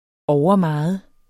Udtale [ ˈɒwʌˈmɑɑð ]